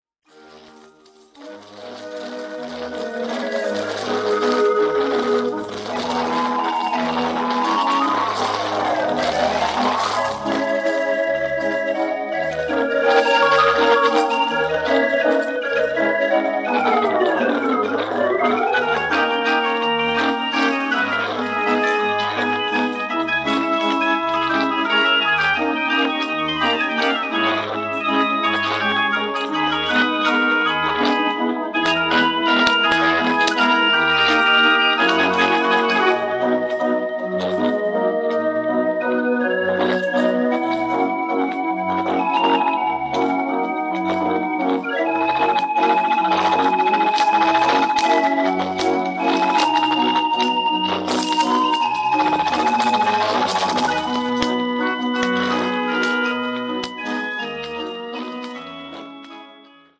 draaiorgelopnamen, uitgevoerd door het
met een helder en karakteristiek klankbeeld.
Formaat 78 toeren
Opnametype Elektrisch opgenomen